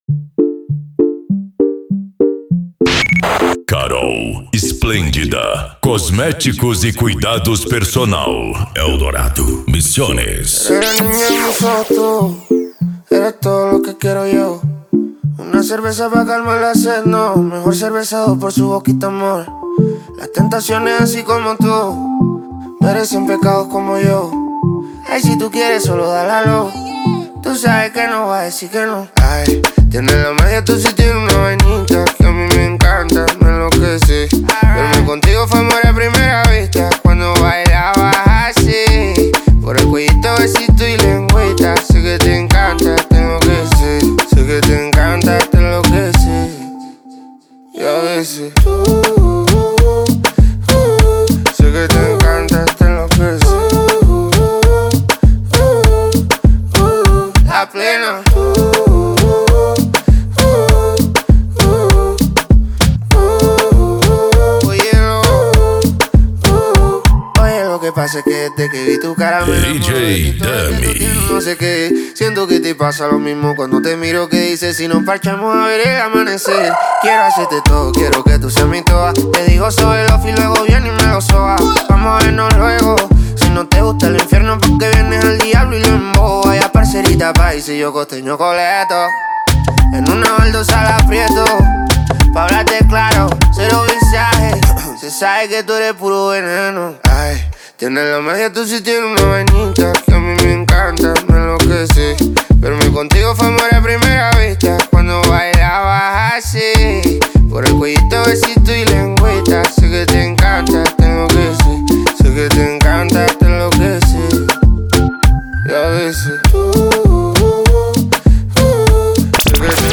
Arrocha
Funk
Reggaeton